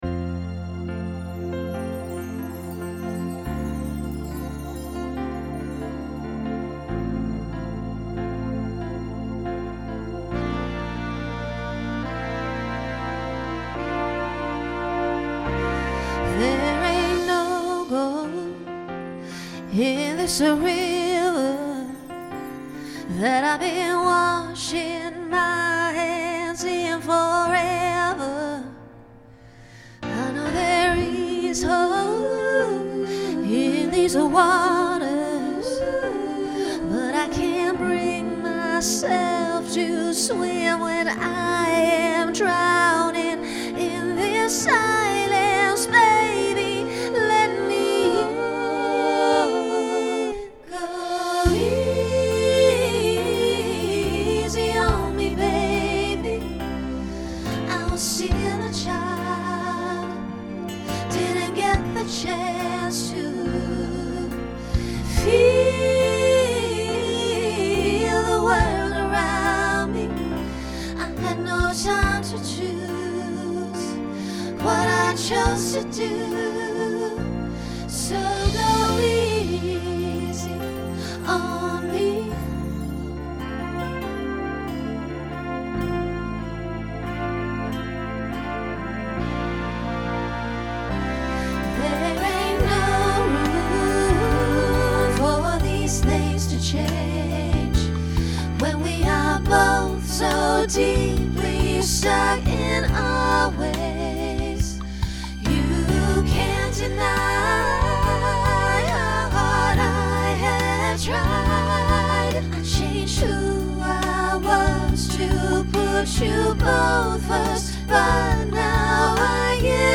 Genre Pop/Dance
Ballad , Solo Feature Voicing SSA